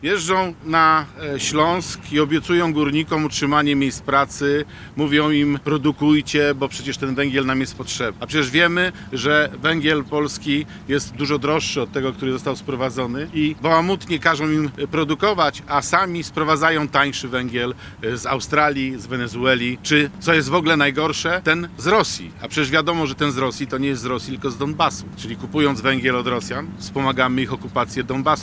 Parlamentarzysta zarzucił rządowi, że polityka węglowa nie idzie w parze z deklaracjami wspierania polskiego górnictwa. Na dowód tego zorganizował konferencję prasową w świnoujskim porcie. W trakcie której, za jego plecami rozładowywany był statek, który przypłynął do Polski z transportem węgla.